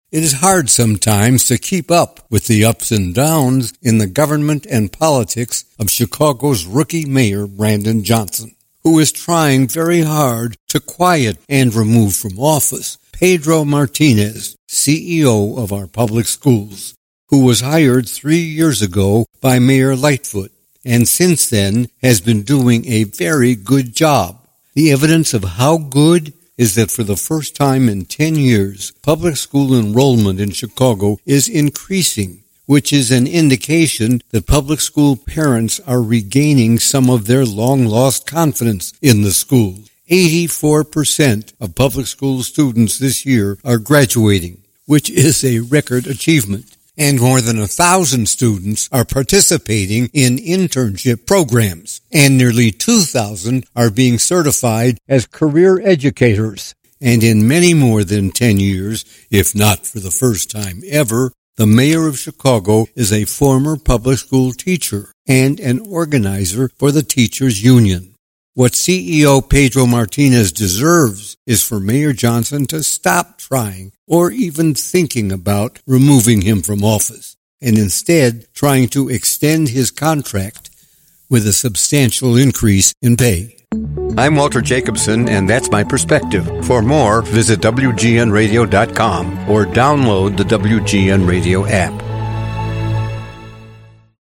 Legendary Chicago journalist Walter Jacobson offers his Perspective on local politics, news and more in this podcast on WGN Plus from WGN Radio in Chicago.